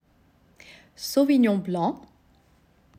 Die korrekte Aussprache der Rebsorte